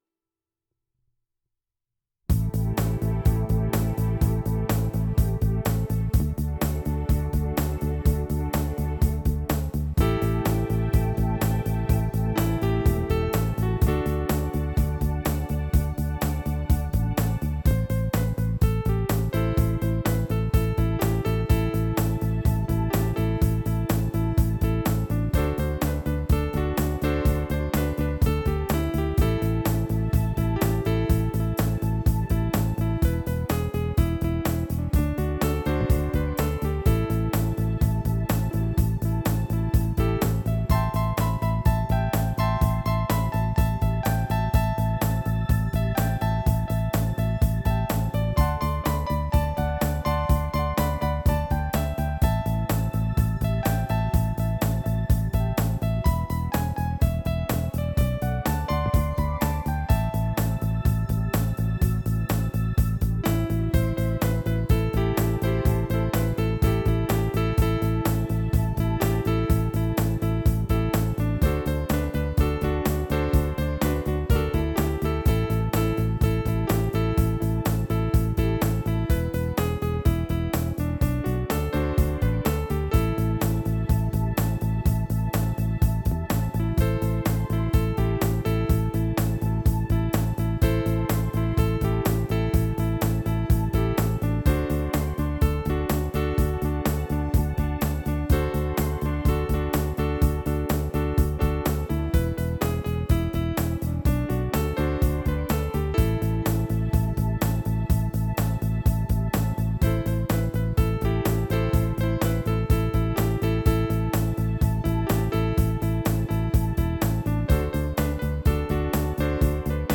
CHRISTIAN ELECTRO: DANCING IN THE JOY OF THE LORD